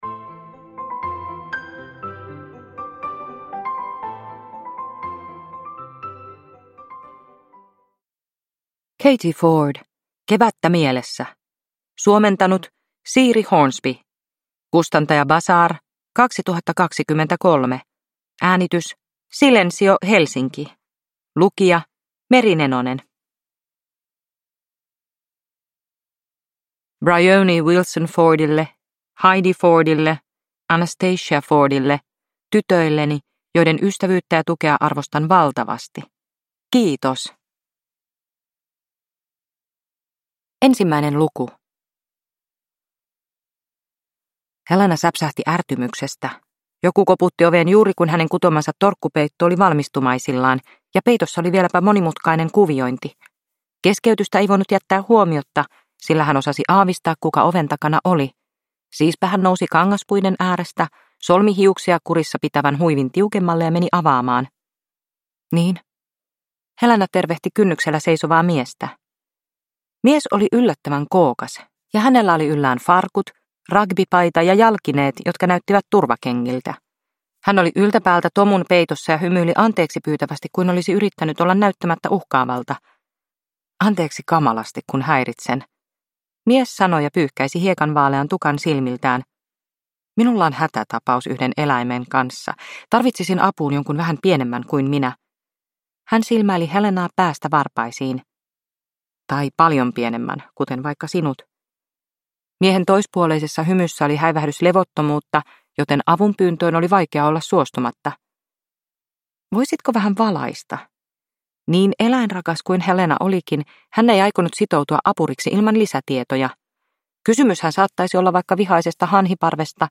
Kevättä mielessä – Ljudbok – Laddas ner